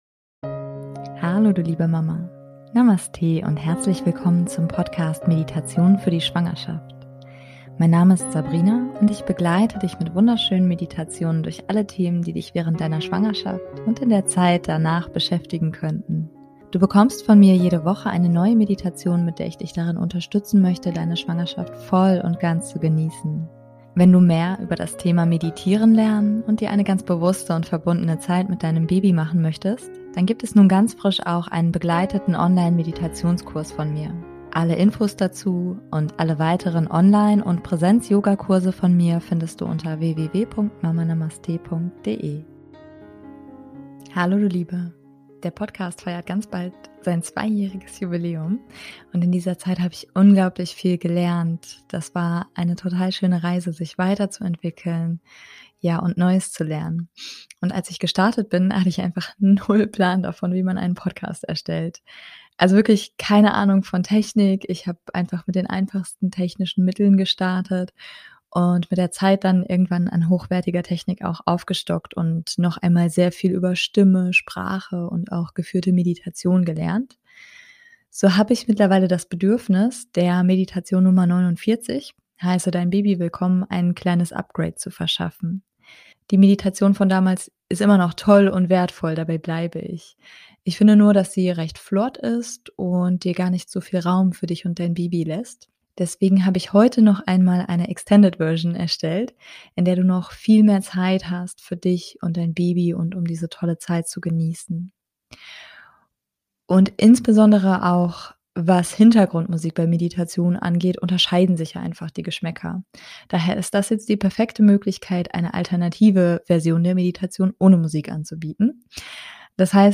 Daher ist das nun eine perfekte Möglichkeit eine alternative Version der Meditation ohne Musik anzubieten.